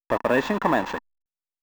APUattack.wav